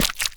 Minecraft Version Minecraft Version 1.21.5 Latest Release | Latest Snapshot 1.21.5 / assets / minecraft / sounds / block / frogspawn / break1.ogg Compare With Compare With Latest Release | Latest Snapshot